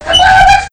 Worms speechbanks
Whatthe.wav